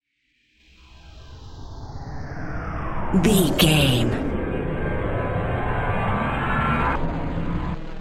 Alien Sweep Lo
Sound Effects
Atonal
scary
ominous
eerie
synthesiser
ambience
pads